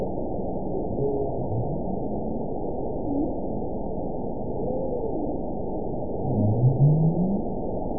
event 922240 date 12/28/24 time 21:28:14 GMT (11 months, 1 week ago) score 9.58 location TSS-AB04 detected by nrw target species NRW annotations +NRW Spectrogram: Frequency (kHz) vs. Time (s) audio not available .wav